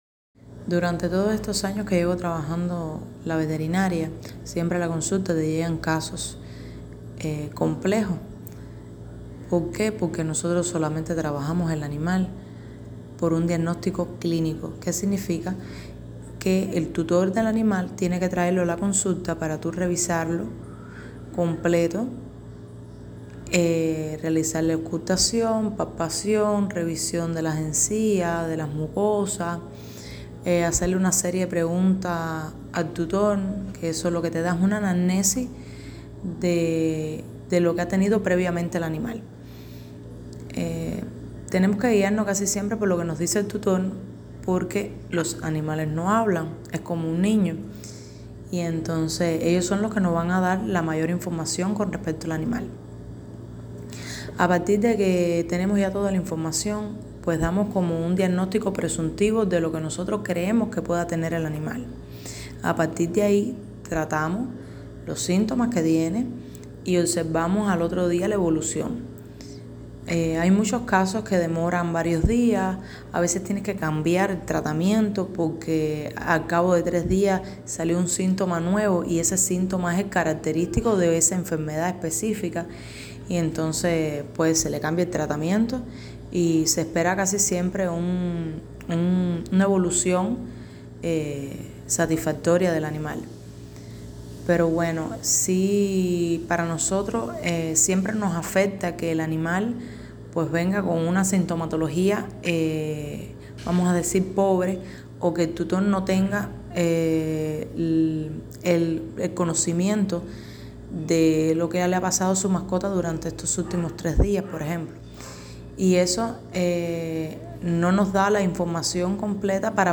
Entrevista Matanzas